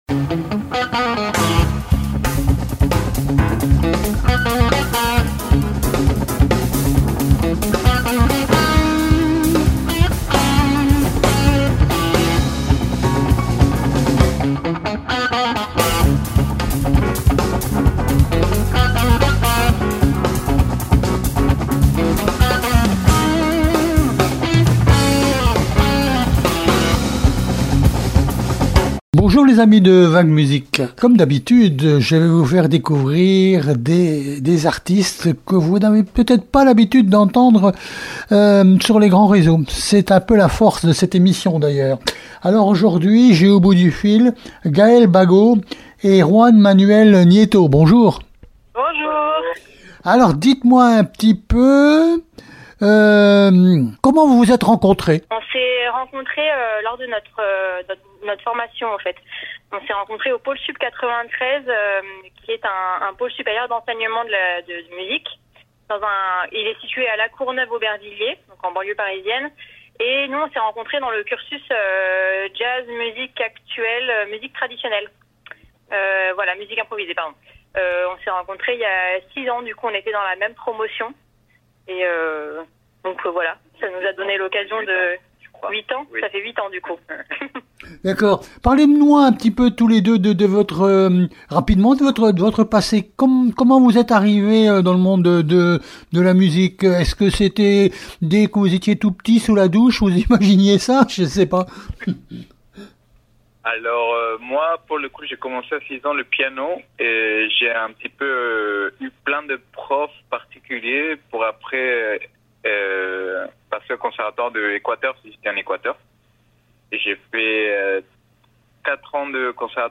interview VAG MUSIC